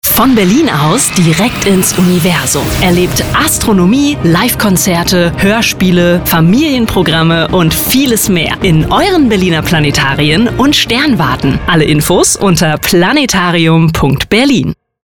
Radiospot